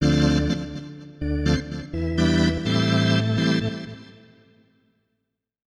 ORGAN013_VOCAL_125_A_SC3(L).wav